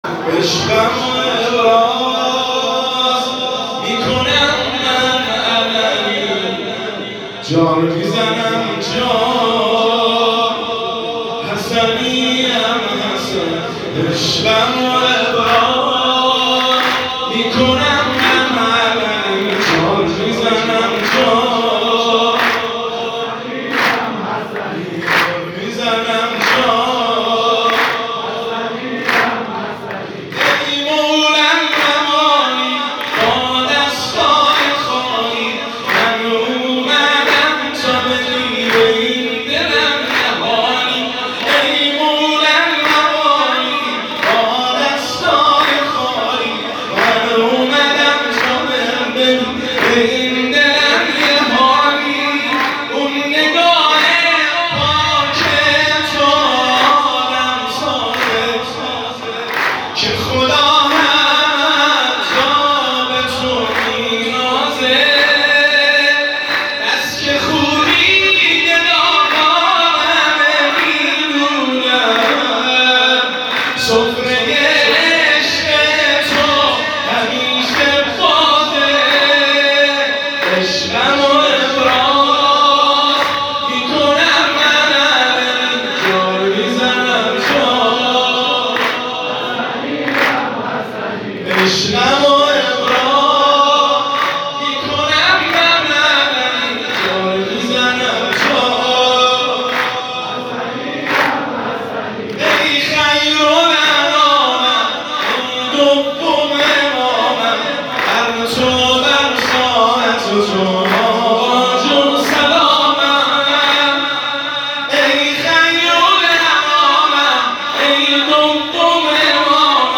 • سرود – میلاد امام حسن مجتبی (ع) 1402